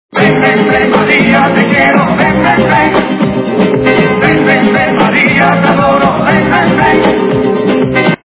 западная эстрада
При заказе вы получаете реалтон без искажений.